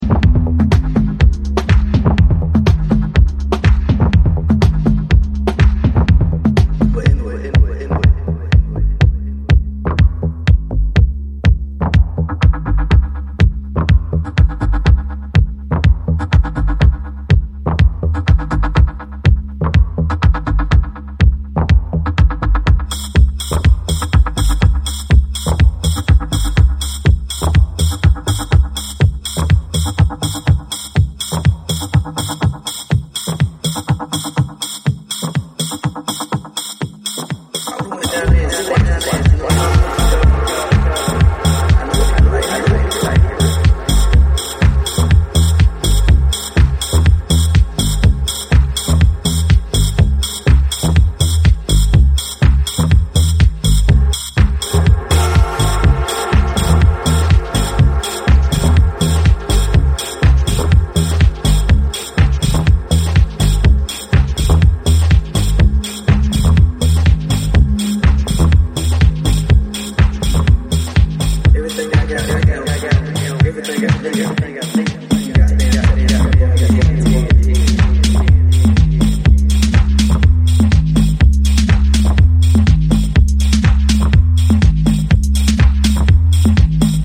venturing deeper into the realms of dub